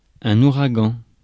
Ääntäminen
Ääntäminen : IPA: /u.ʁa.ɡɑ̃/ France: IPA: /u.ʁa.ɡɑ̃/ Haettu sana löytyi näillä lähdekielillä: ranska Käännös Konteksti Ääninäyte Substantiivit 1. hurricane meteorologia, sää US 2. typhoon US Suku: m .